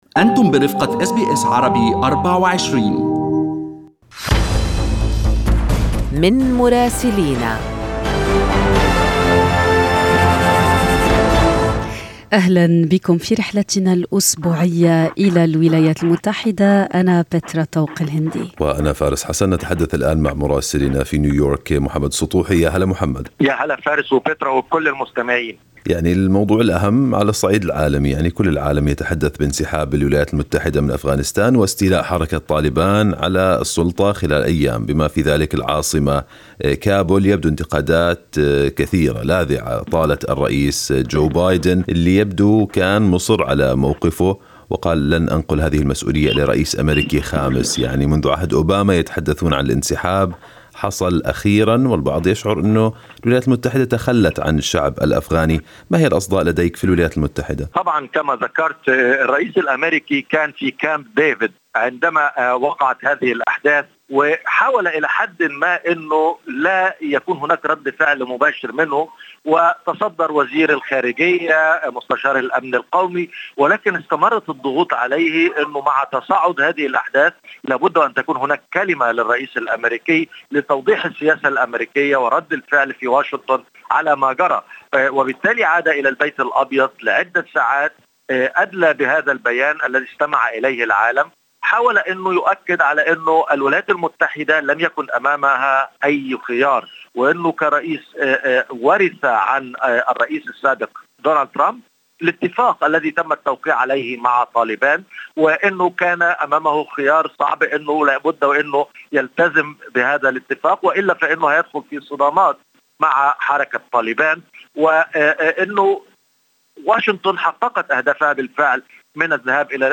من مراسلينا: أخبار الولايات المتحدة الأمريكية 19/8/2021